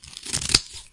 隆隆声和沙沙声 " 剪刀剪 1
描述：剪切通过几层纸的剪刀 除非另有说明，否则在Behringer Eurorack UB802上使用舒尔PG57记录。一些声音用接触式麦克风或智能手机录制。
Tag: 切片 切割 剪刀 剪断